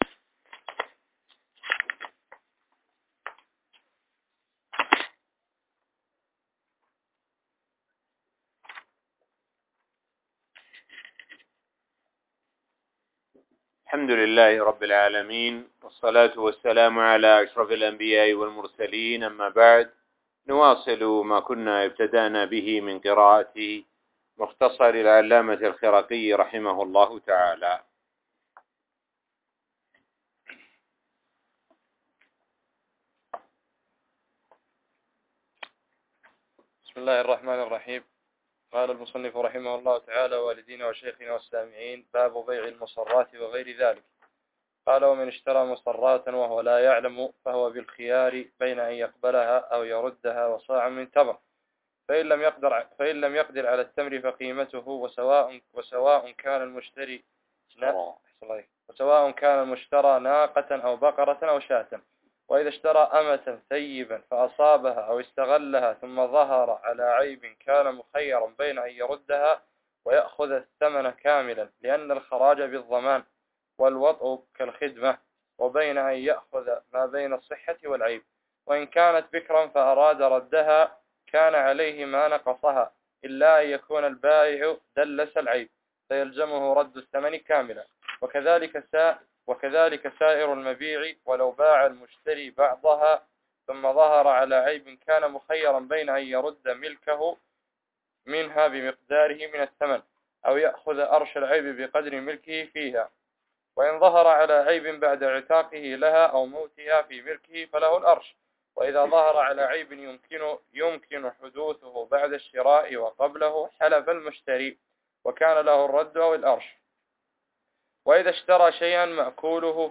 الدرس--25 باب بيع المصراة وغير ذلك السابق play pause stop mute unmute max volume